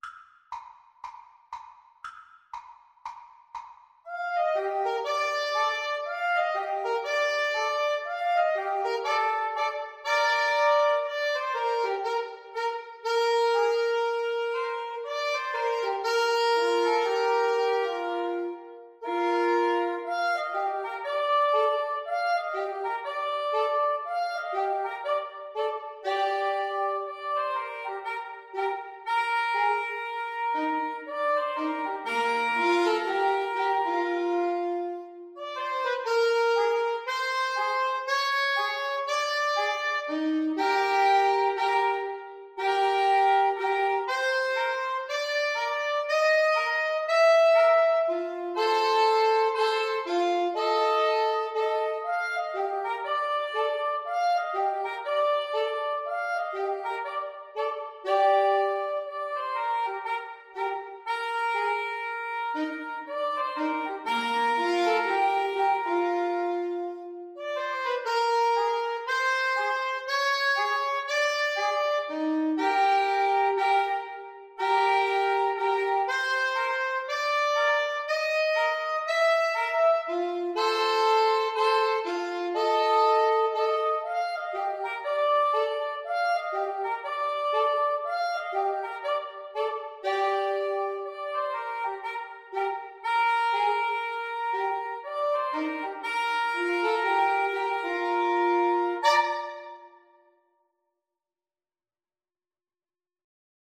Allegro (View more music marked Allegro)
Woodwind Trio  (View more Intermediate Woodwind Trio Music)
Jazz (View more Jazz Woodwind Trio Music)